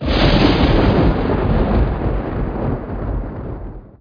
RAIN_8.mp3